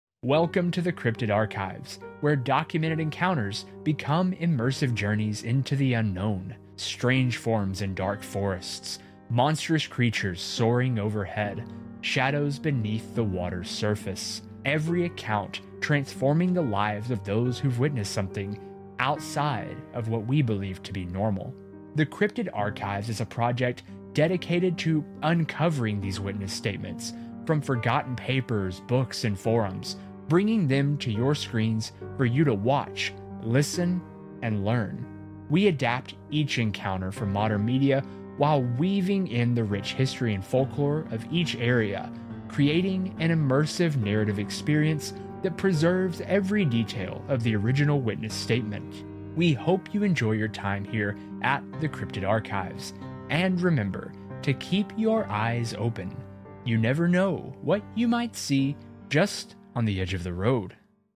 Are you ready for an immersive narrative audio experience? 👀 Our NEW podcast, The Cryptid Archives, is a project dedicated to uncovering these witness statements from forgotten papers, books, forums, and folklore, bringing them to your screens for you to watch, listen, and learn.